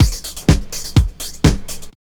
B5HOUSE125.wav